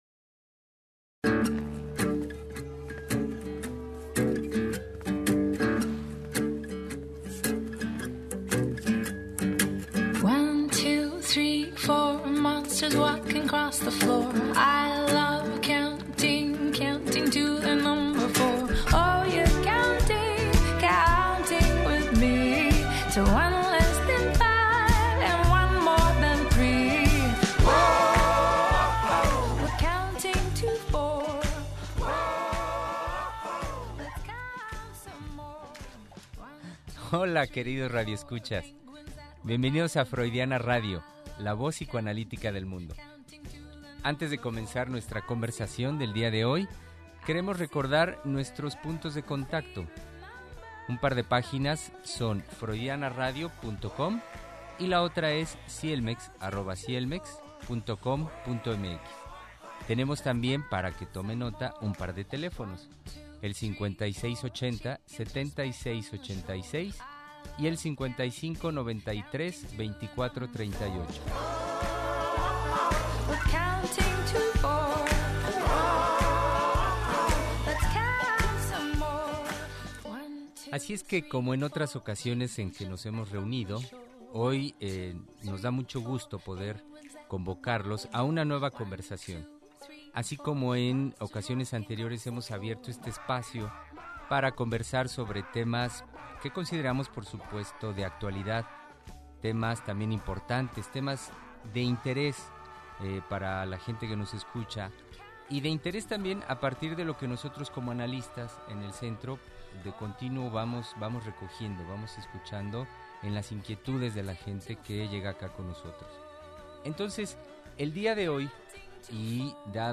Niños del siglo XXI. Conversación psicoanalítica con motivo del día del niño – Freudiana radio